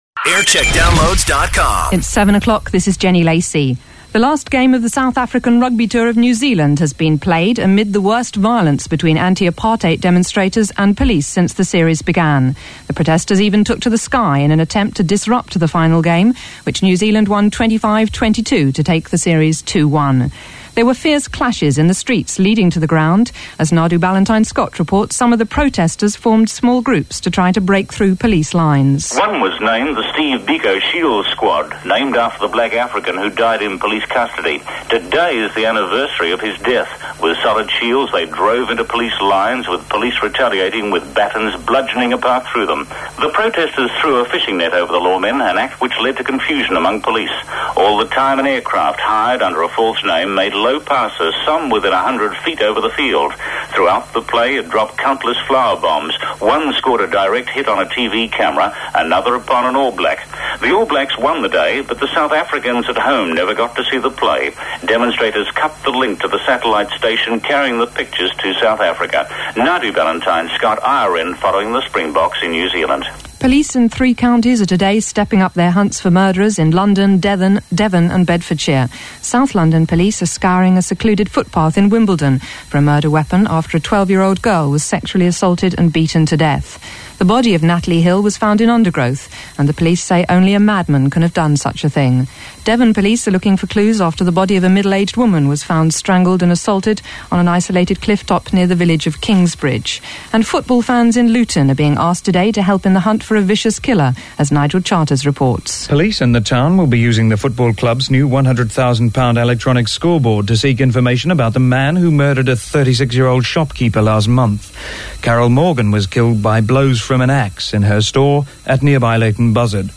7am News